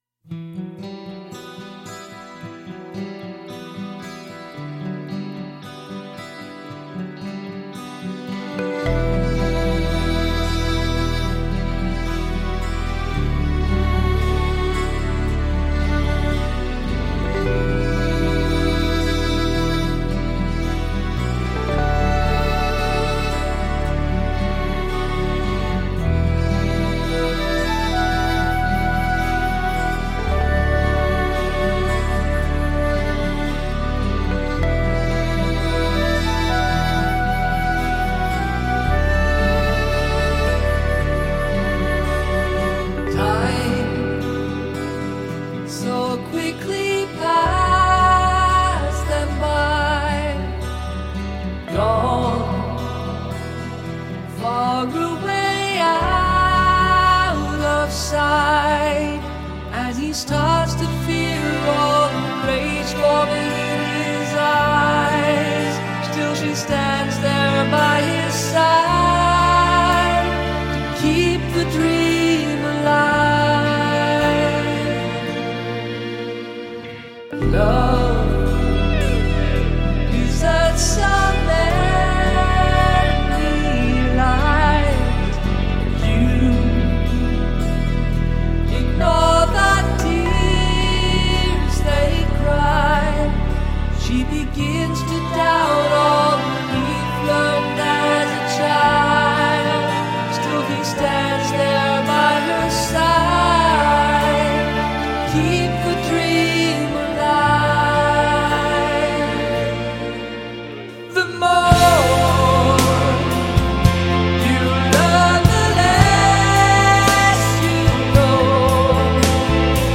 melodic AOR prog